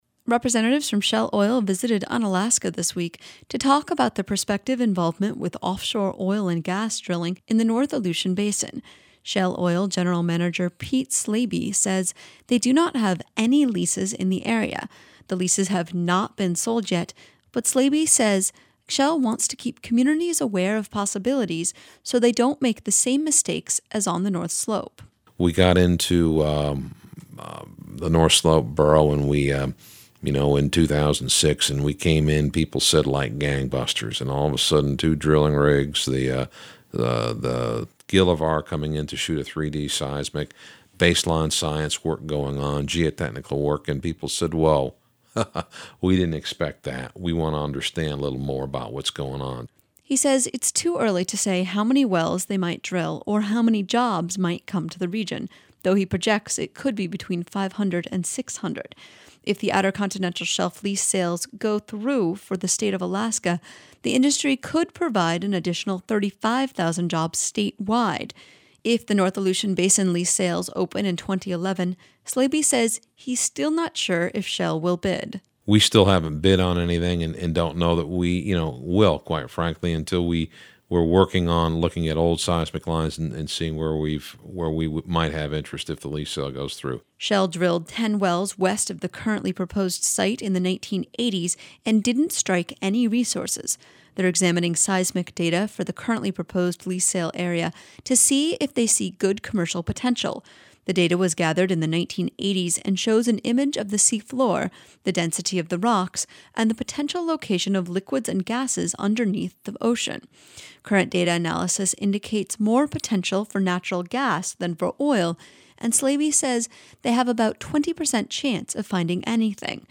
shell-oil-visits-unalaska.mp3